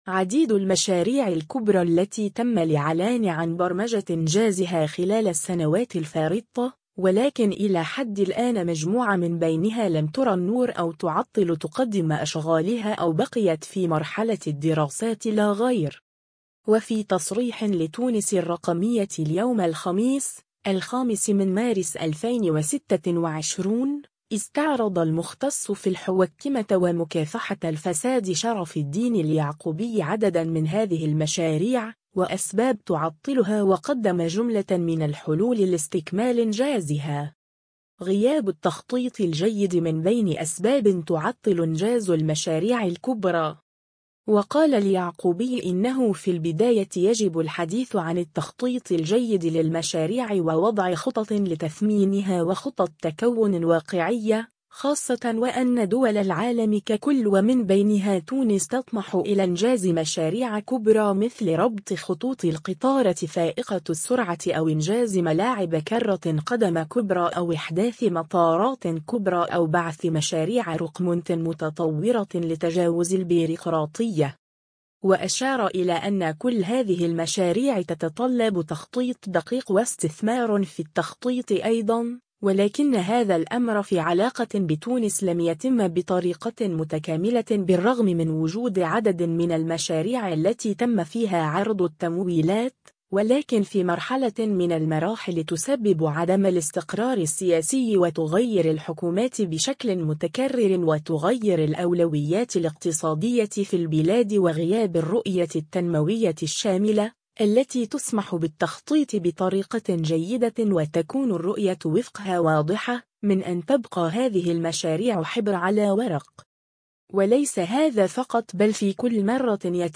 تعطّل انجاز عدد من المشاريع الكبرى في تونس… مختص في الحوكمة يكشف الأسباب و يوضح الحلول [فيديو]